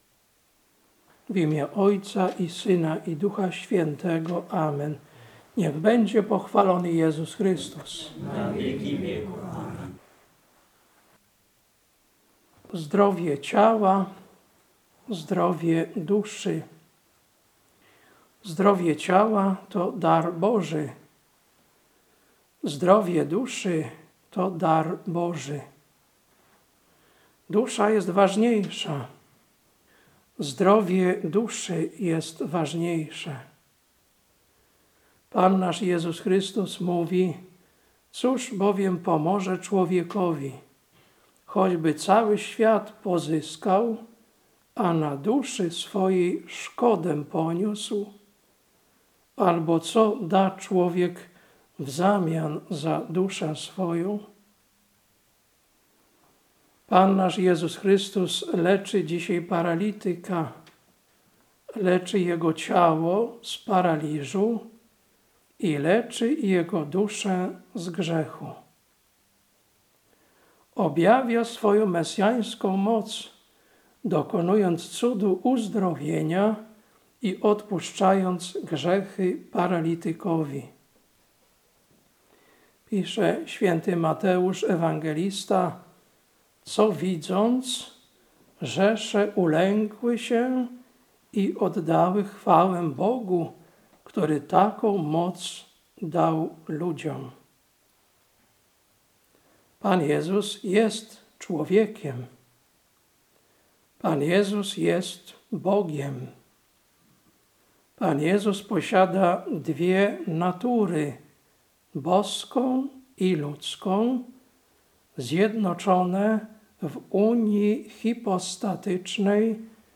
Kazanie na XVIII Niedzielę po Zesłaniu Ducha Świętego, 12.10.2025 Ewangelia: Mt 9, 1-8